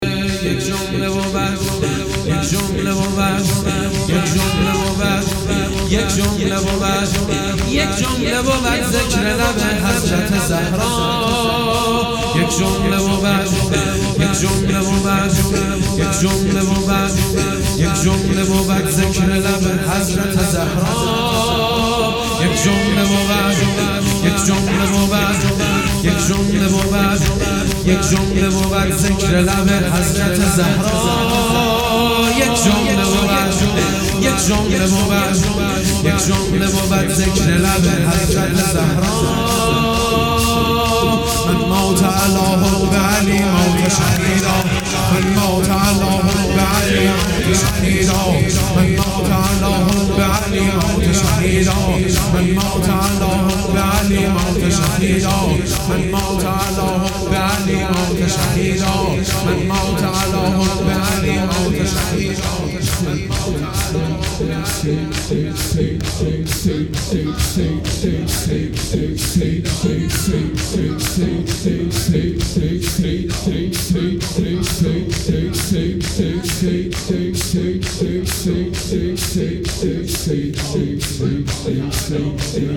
نوا | یک جمله بود ذکر لب حضرت زهرا(ُس)
شهادت حضرت زهرا(ُس) - سه شنبه17بهمن1396